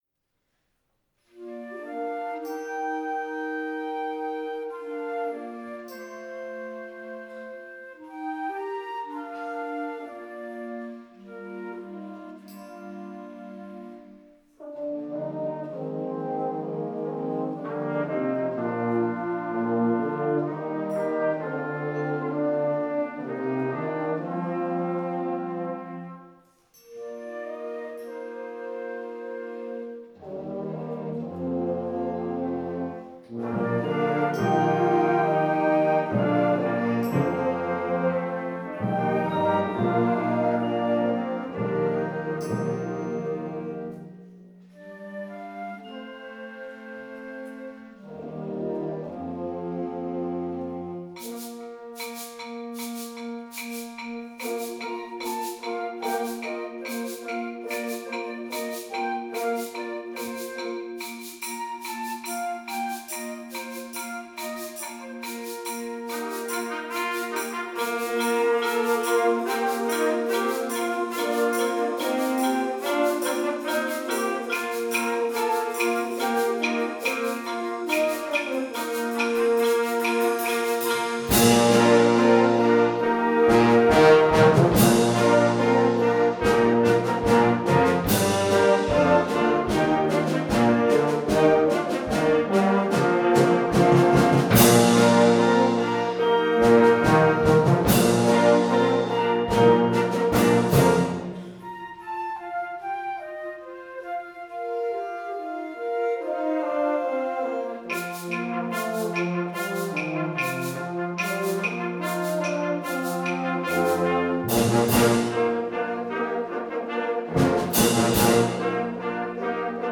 Schulorchester
Konzertwertung 2017, Riedau, Pramtalsaal